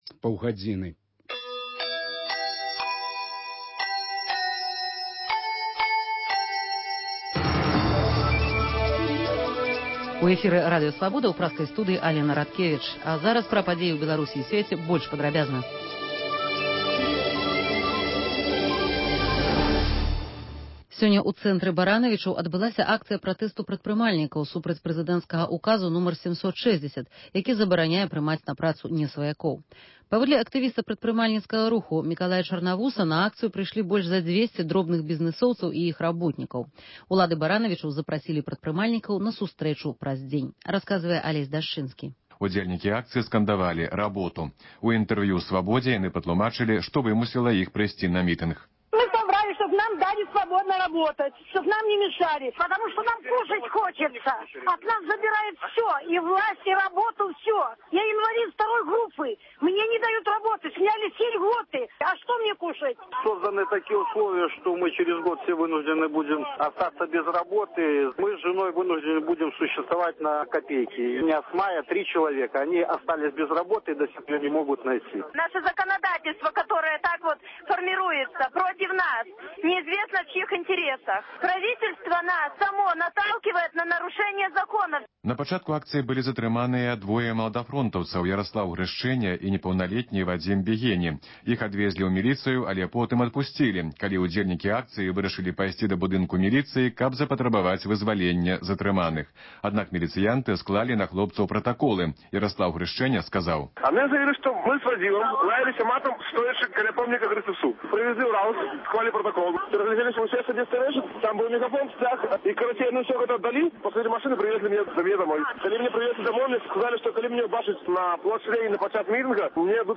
Паведамленьні карэспандэнтаў "Свабоды", званкі слухачоў, апытаньні на вуліцах беларускіх гарадоў і мястэчак.